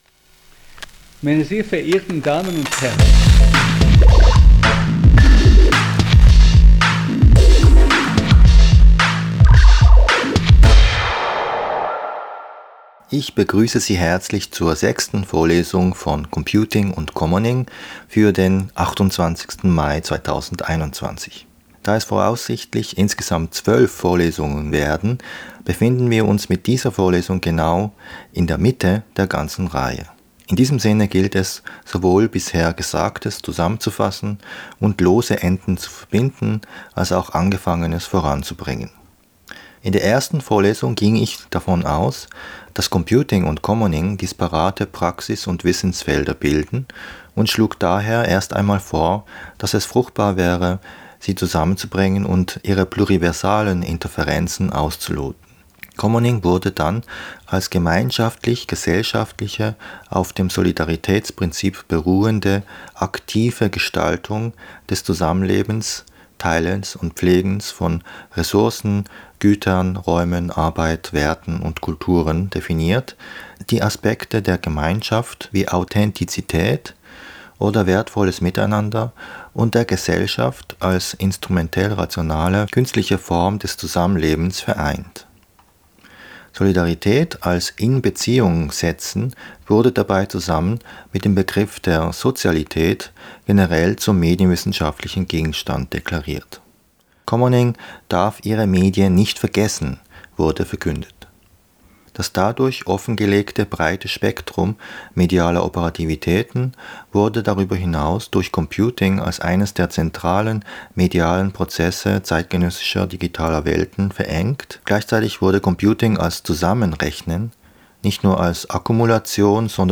Podcastvorlesung